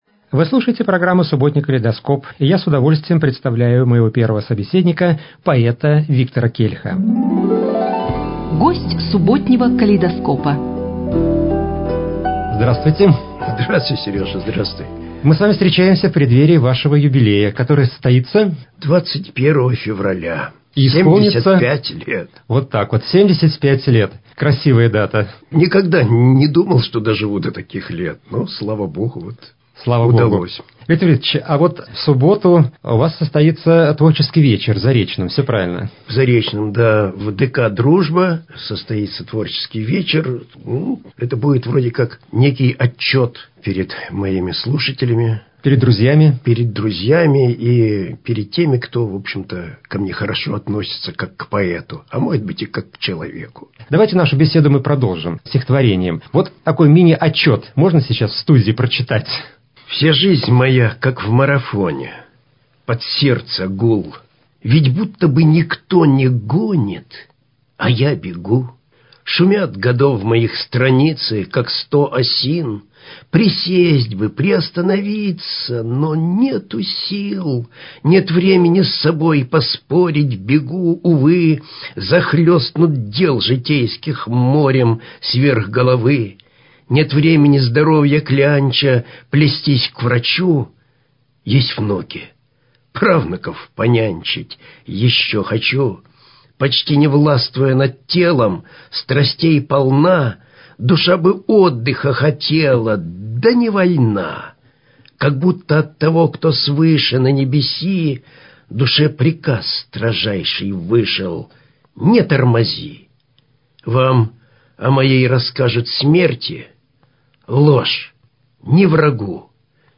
Запись беседы